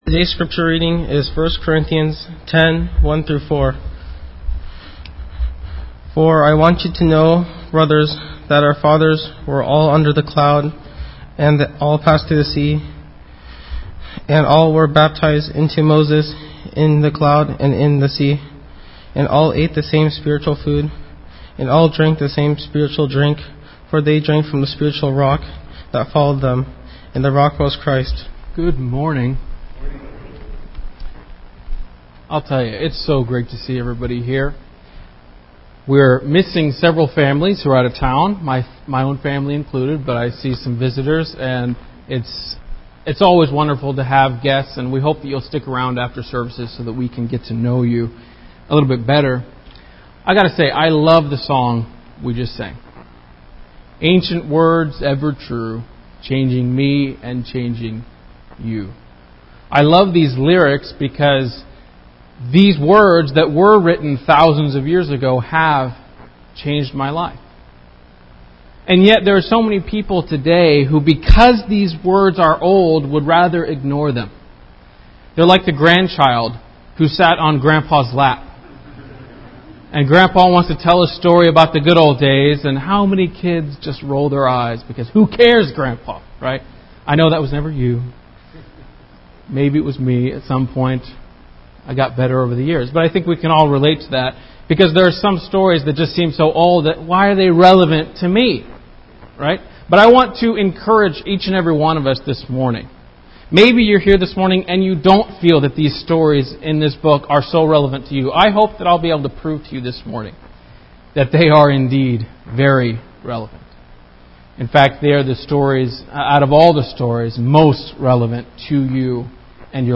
The scripture reading for this lesson was 1 Corinthians 10:1-4